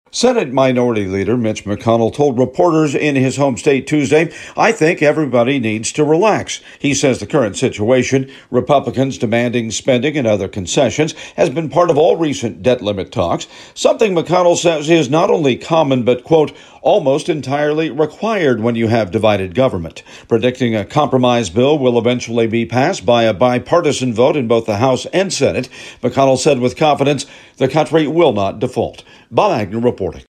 Reporting from Capitol Hill